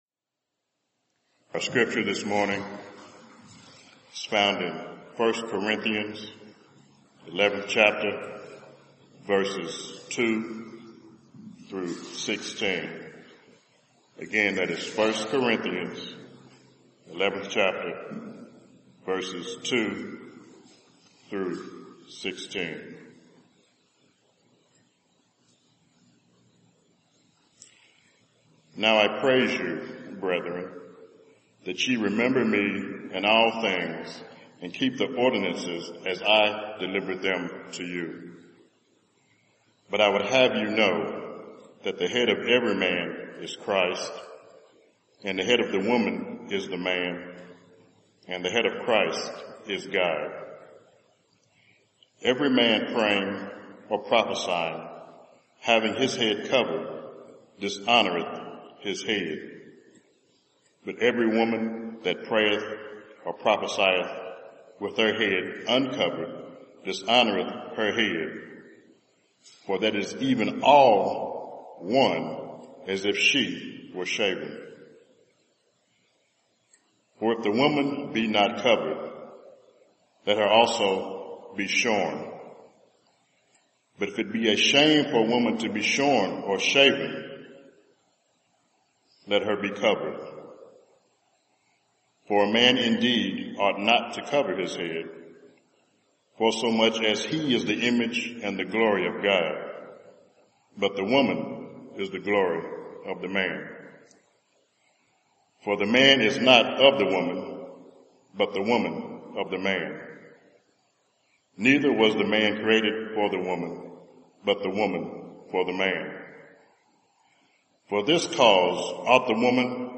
Passage: 1 Corinthians 11:3-16 Service Type: Sunday Morning